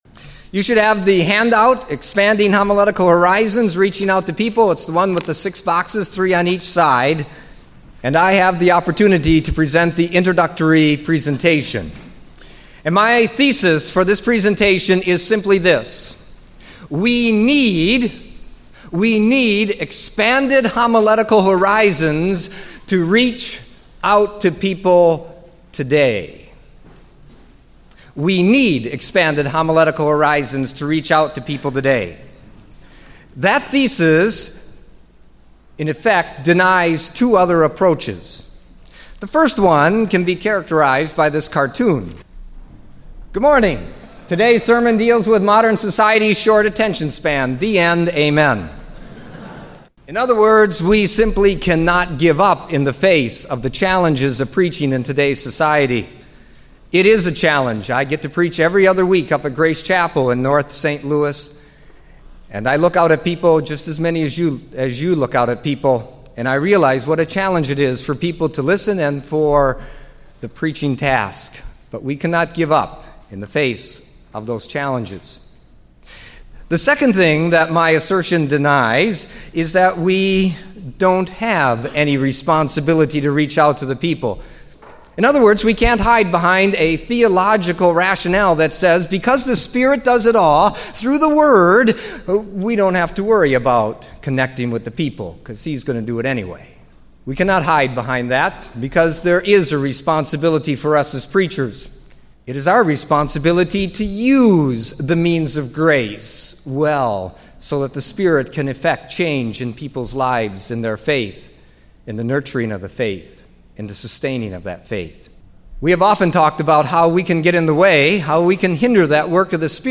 Theological Symposium 1999
Bible Study; Lecture; Sermon Prep